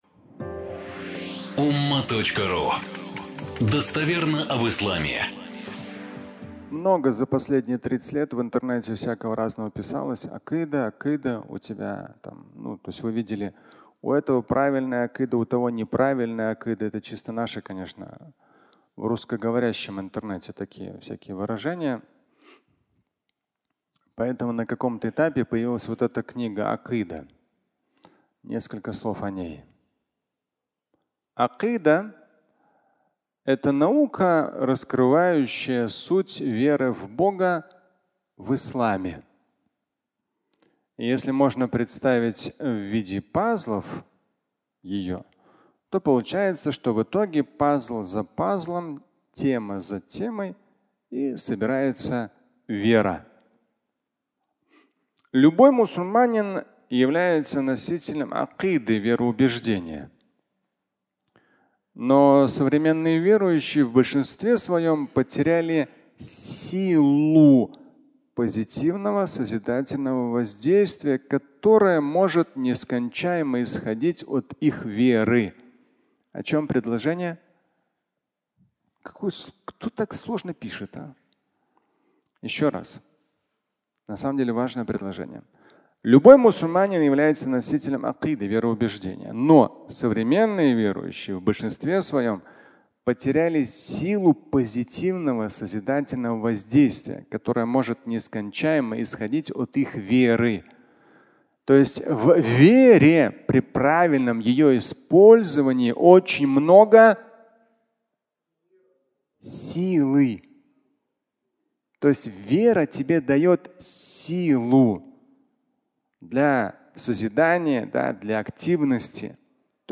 Акыда (аудиолекция)
Фрагмент пятничной лекции